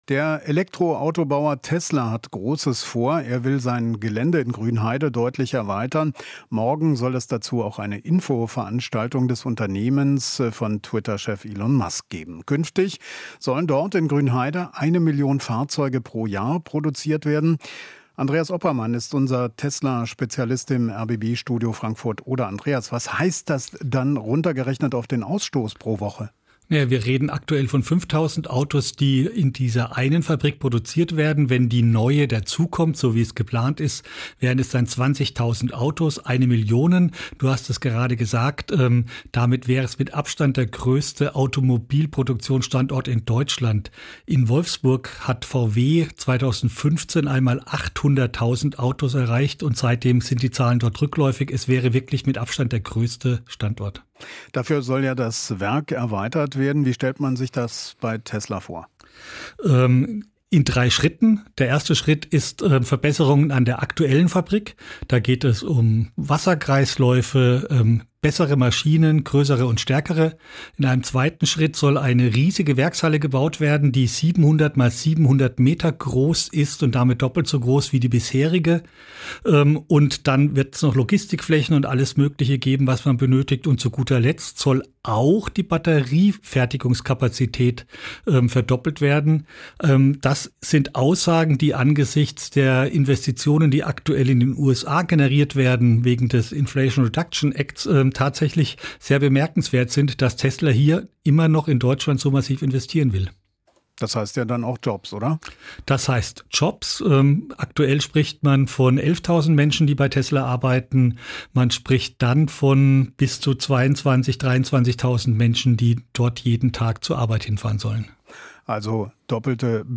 Interview - Tesla will künftig eine Million Fahrzeuge im Jahr produzieren